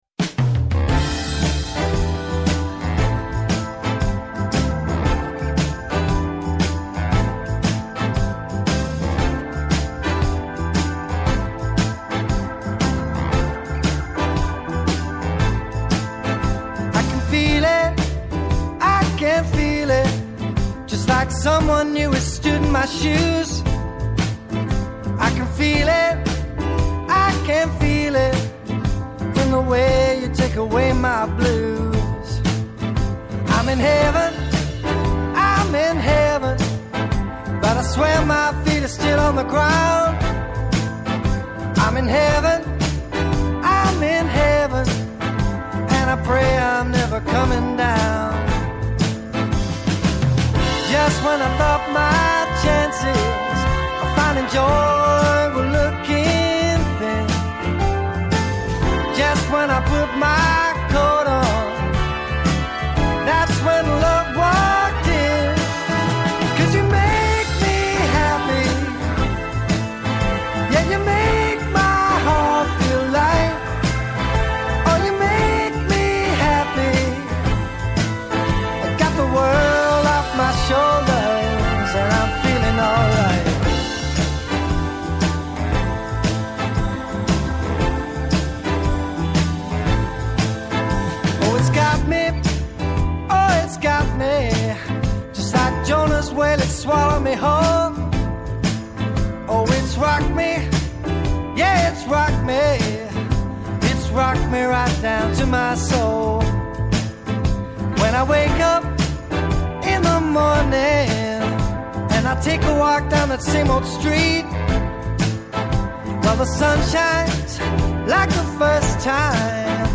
Πόση επικαιρότητα μπορεί να χωρέσει σε μια ώρα; Πόσα τραγούδια μπορούν να σε κάνουν να ταξιδέψεις;